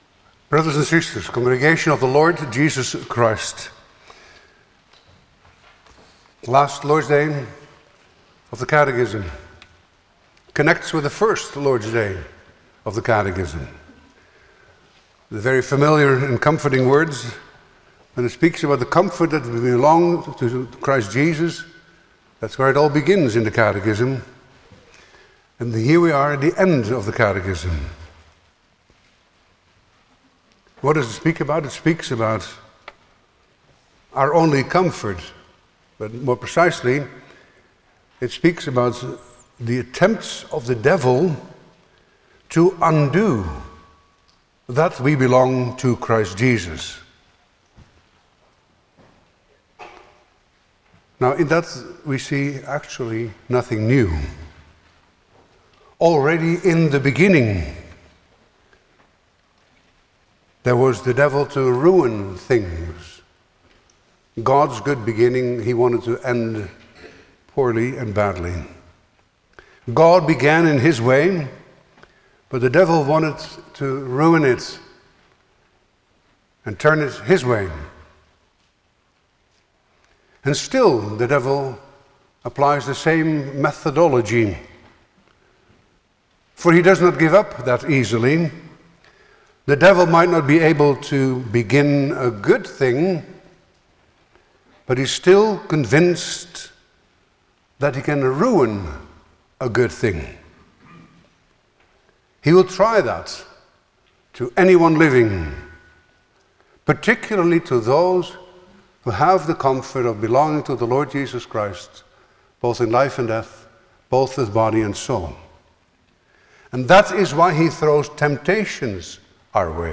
Passage: Lord’s Day 52 Service Type: Sunday afternoon
07-Sermon.mp3